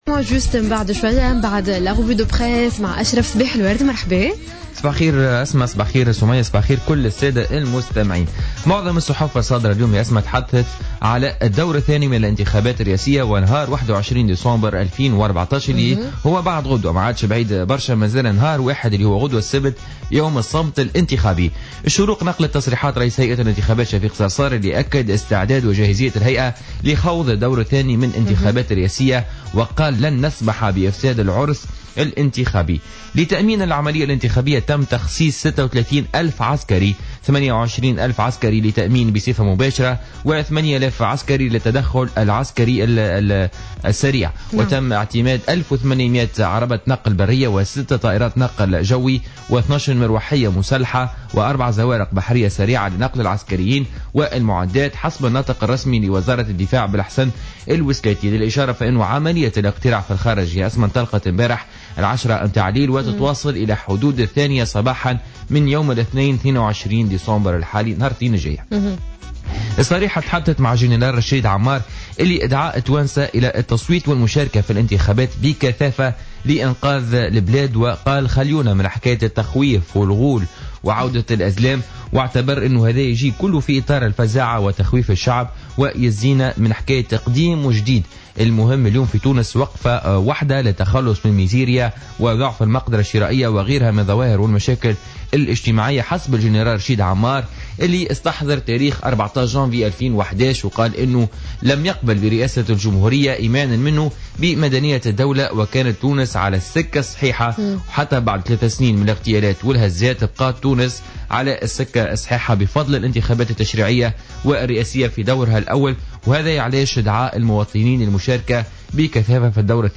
معرض الصحافة ليوم الجمعة 19-12-14